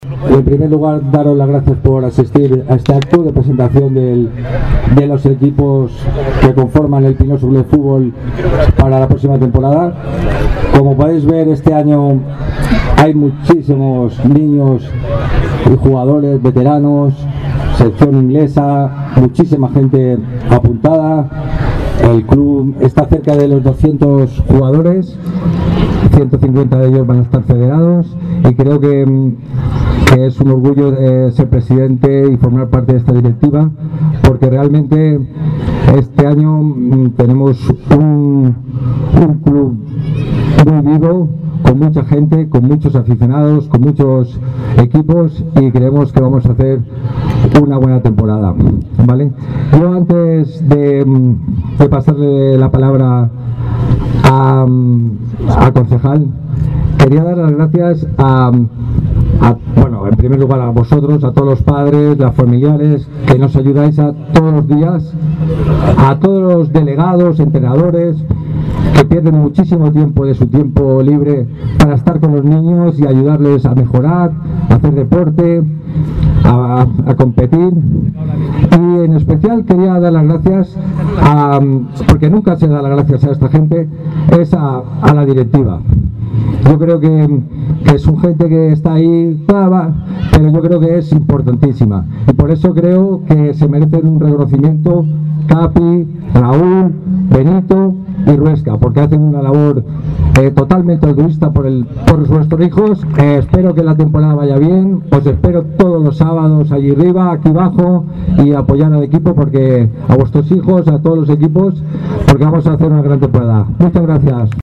El Pinoso CF presenta oficialmente a sus equipos a la afición
El campo de fútbol Perfecto Rico Mira ha acogido la presentación de todas las categorías del Pinoso CF, para la temporada 2024/2025 acogiendo a numeroso público que no se ha querido perder la presentación oficial de todos los equipos que compiten esta temporada.